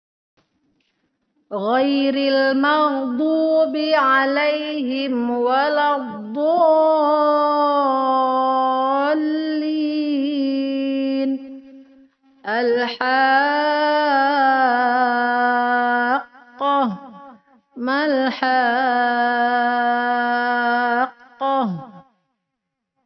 Panjang 6 harokat sama dengan 3x ayun suara.